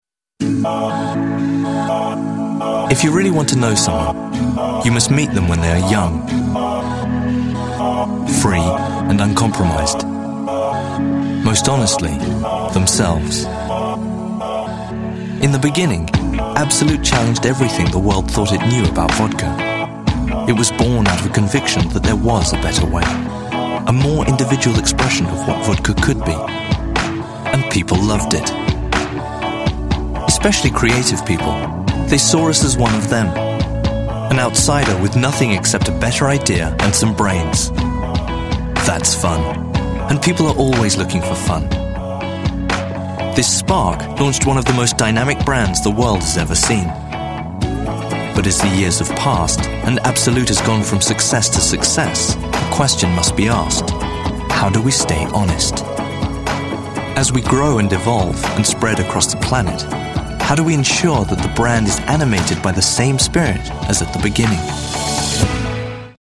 Young British male - cool
Sprechprobe: Industrie (Muttersprache):